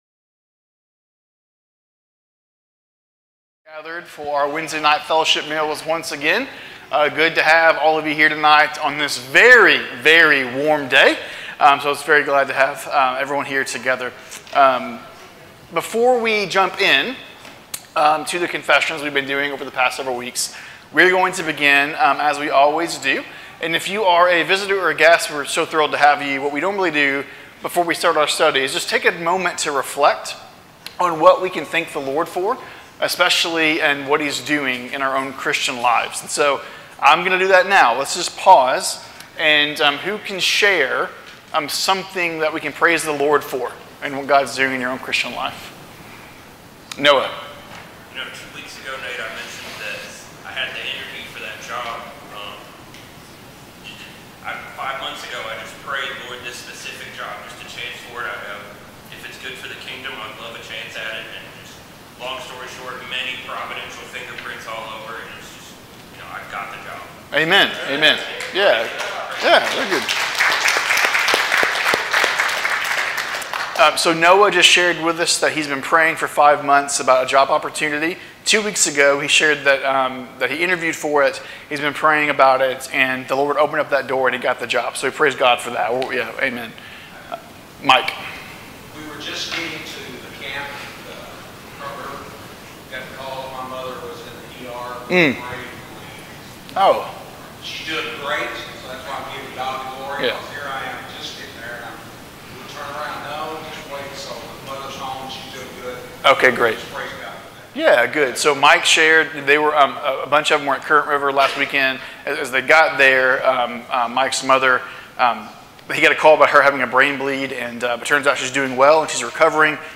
Sermons | Waldo Baptist Church
Wednesday Evening Bible Studies - 6:30pm